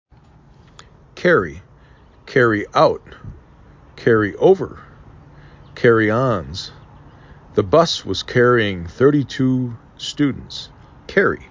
car ry
k e r E